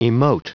Prononciation du mot : emote
emote.wav